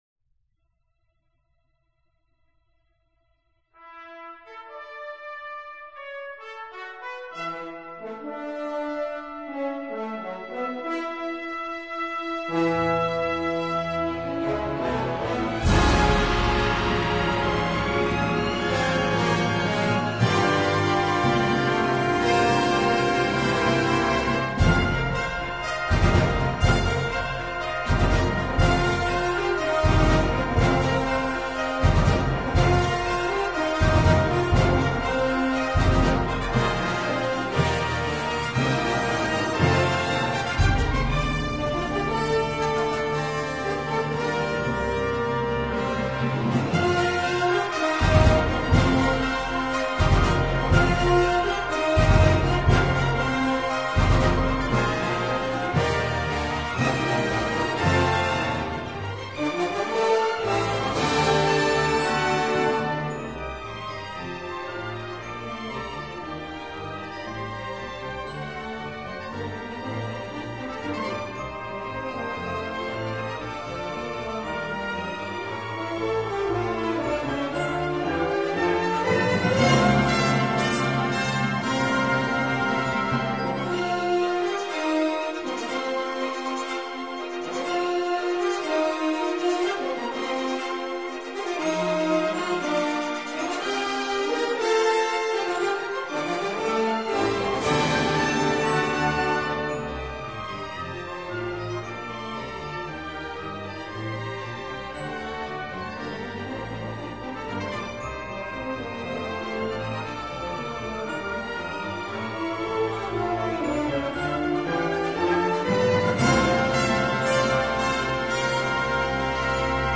音乐配合着电影快节奏的气氛，呈现一种壮阔蓬勃的气势，宛如星际大航在宇宙间缓缓而行，有一股震慑人心的气魄。